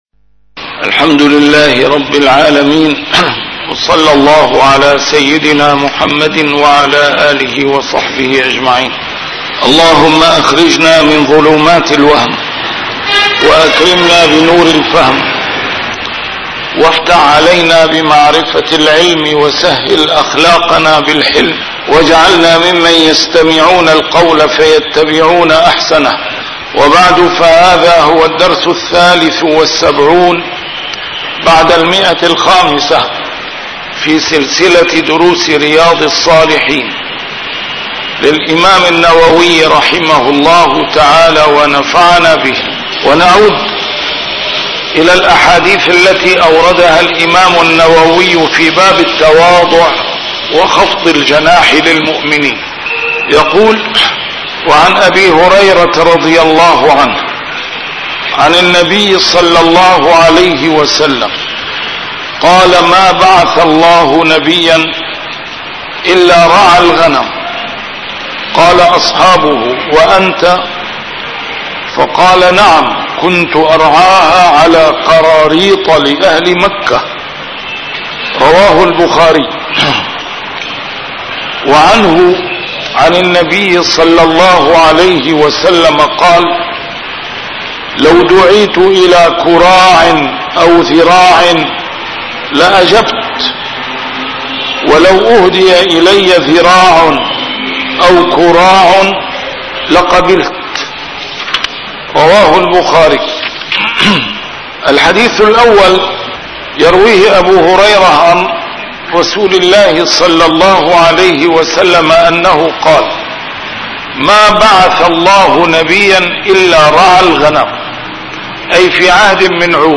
A MARTYR SCHOLAR: IMAM MUHAMMAD SAEED RAMADAN AL-BOUTI - الدروس العلمية - شرح كتاب رياض الصالحين - 573- شرح رياض الصالحين: التواضع